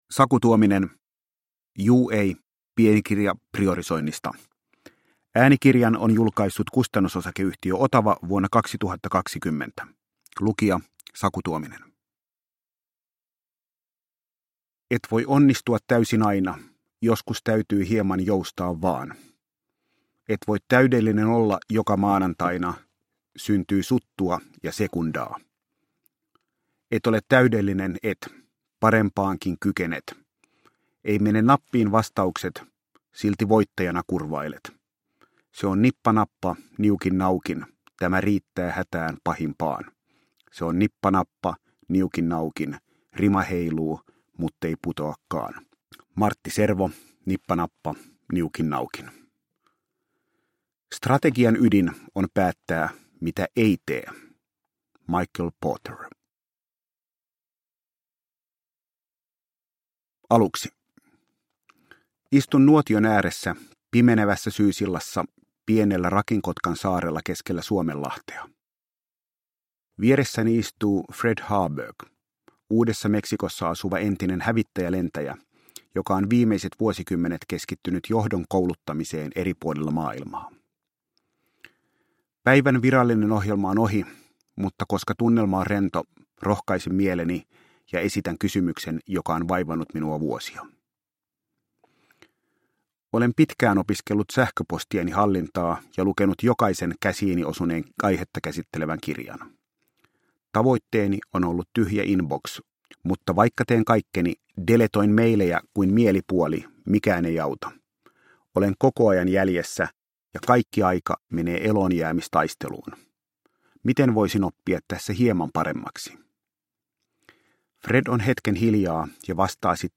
Juu ei – Ljudbok – Laddas ner